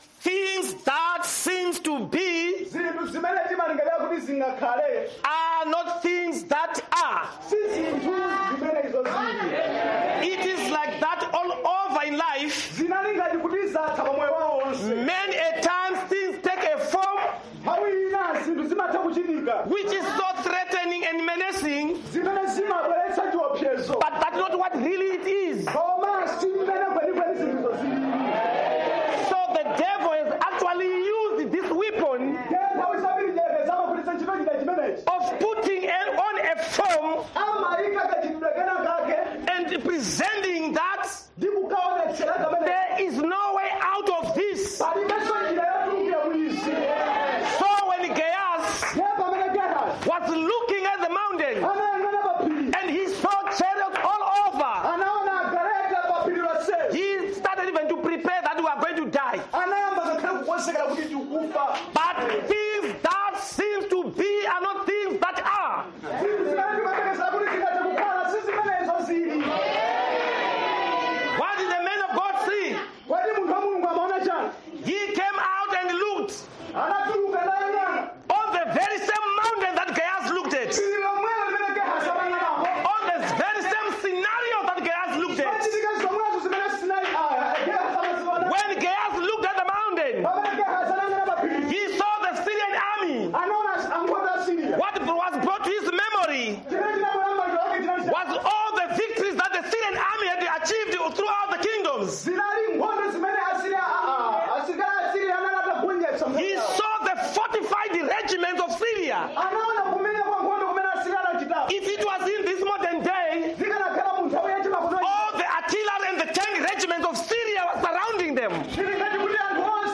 This message was preached in Tonje, Malawi.